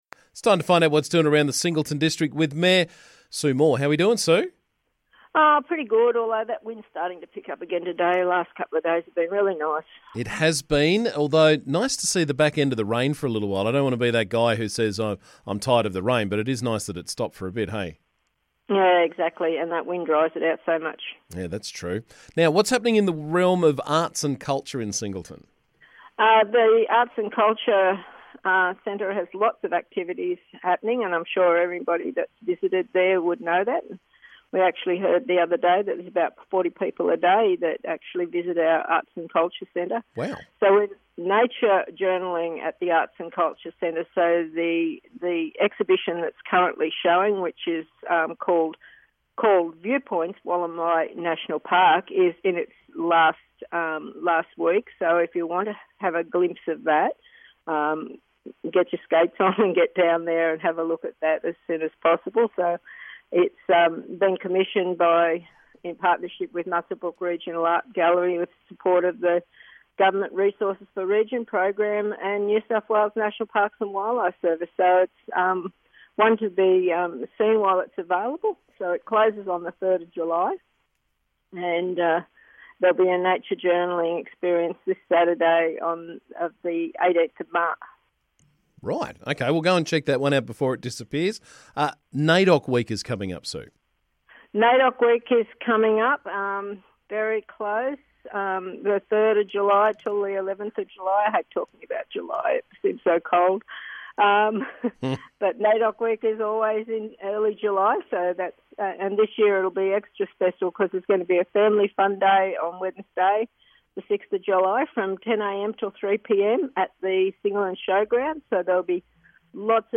We caught up with Singleton Council Mayor Sue Moore to find out what's doing around the district.